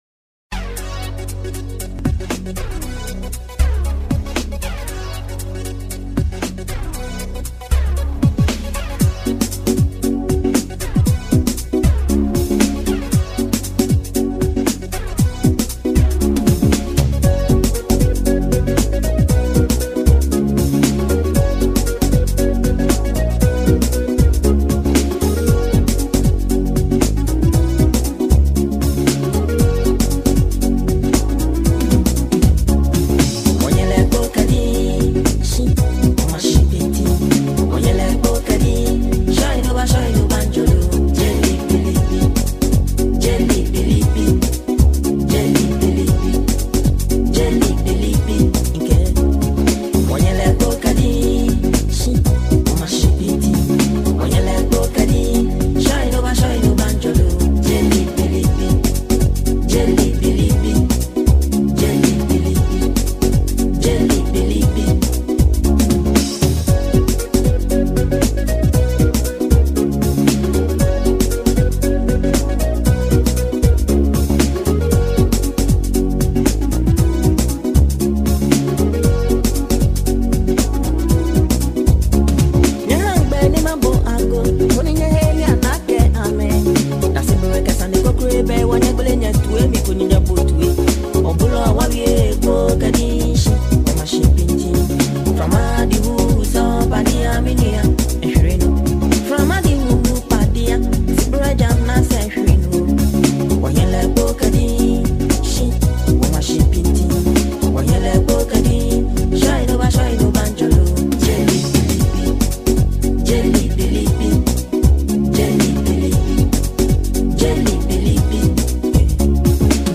90s Music
old highlife song